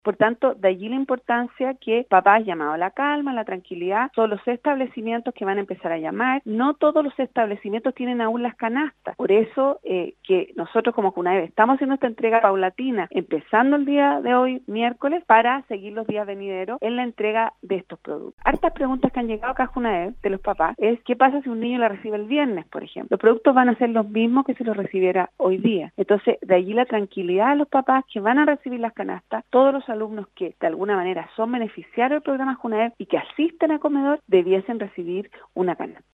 Ante algunas imprecisiones en relación a las canastas alimenticias que estará entregando (Junaeb) en Atacama, la directora de la institución, Claudia Alvayai Rojas, sostuvo un contacto telefónico con Nostálgica donde preciso la información que viene circulando por distintas redes sociales.